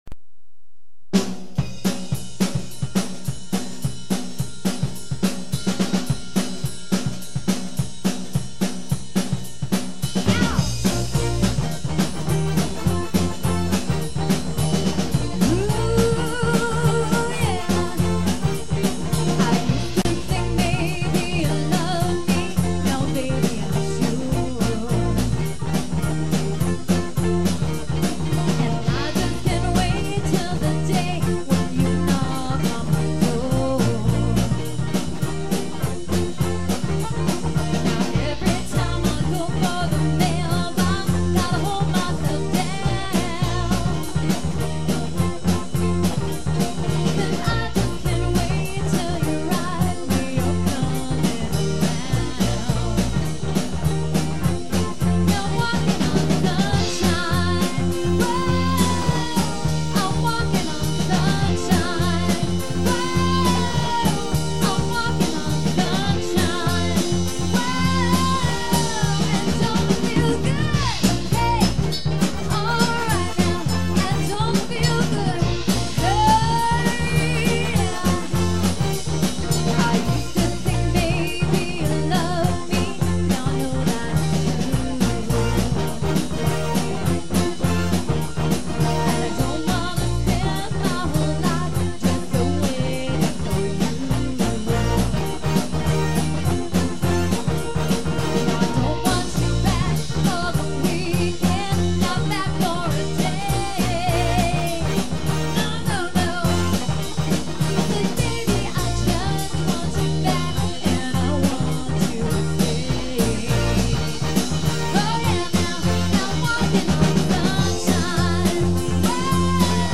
we were a more mellow dance band.